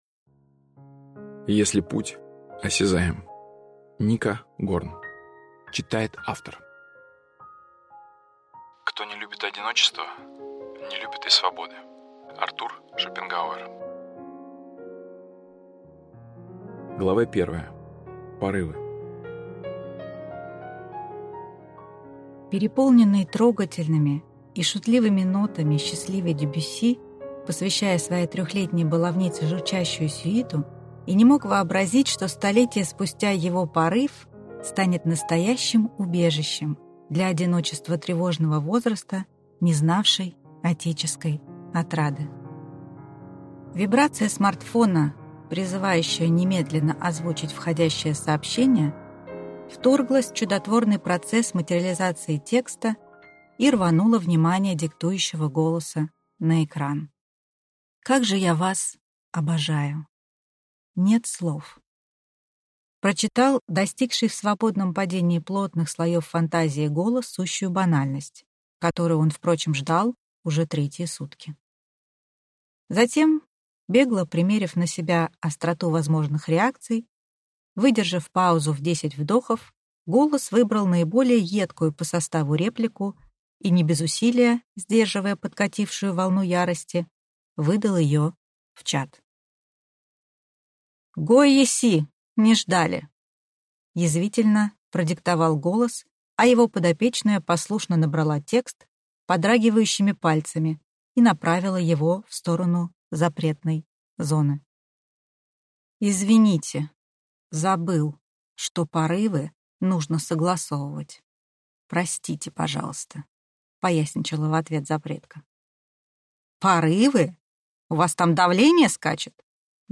Аудиокнига Если путь осязаем | Библиотека аудиокниг